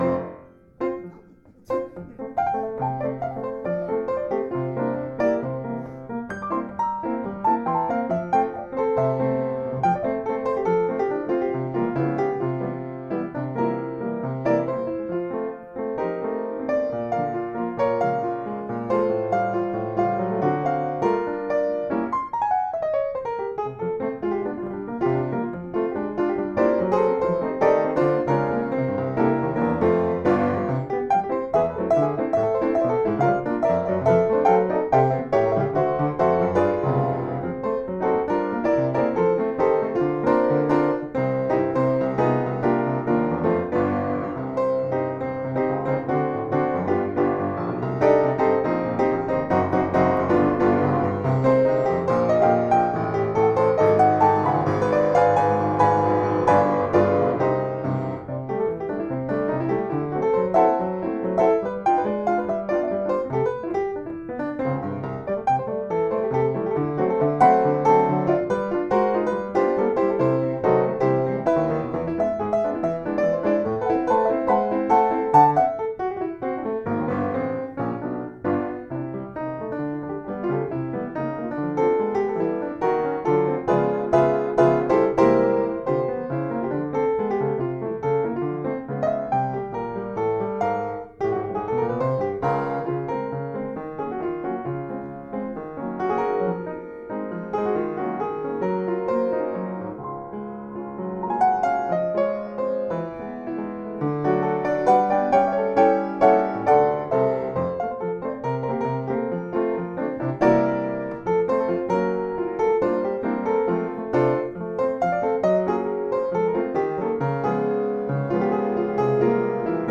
Piano solo improvisation composition percussions voix mélodica
Fixer celà sur un disque est actuellement le projet principal .L’enregistrement réalisé sur piano Steinway est en cours de mixage.